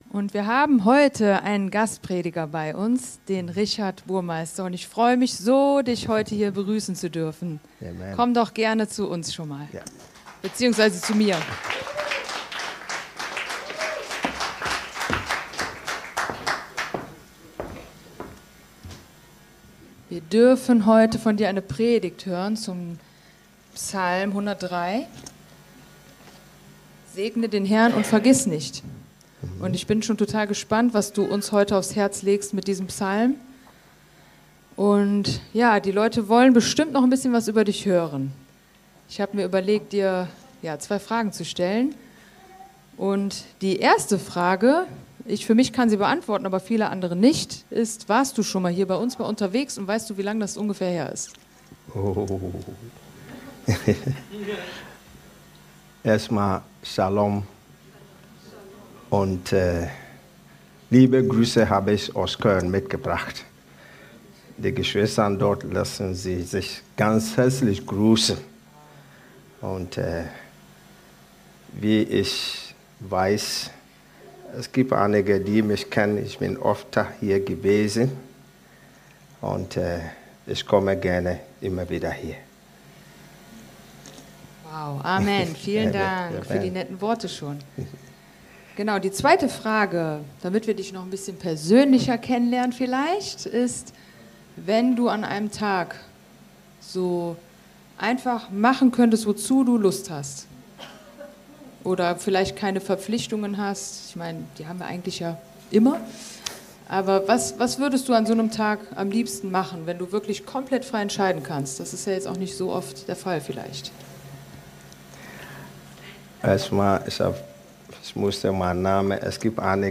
Segne den HERRN und vergiss nicht ~ Predigt-Podcast von unterwegs FeG Mönchengladbach Podcast